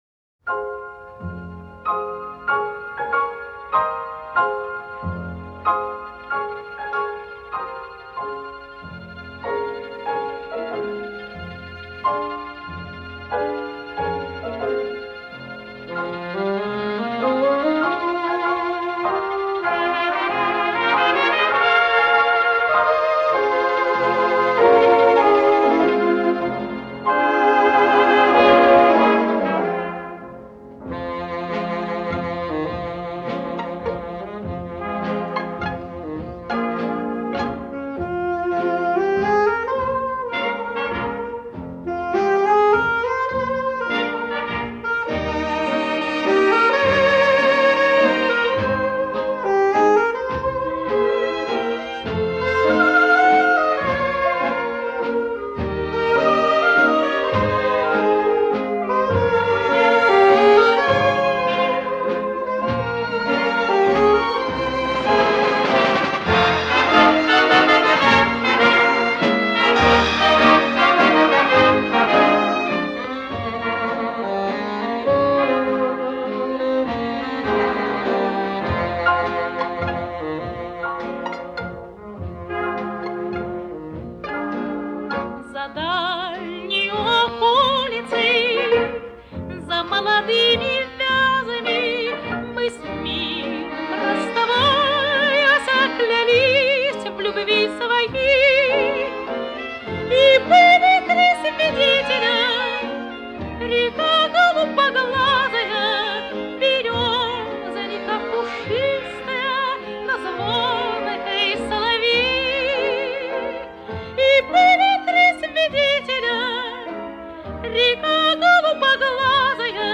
Какой чудесный чистый голос!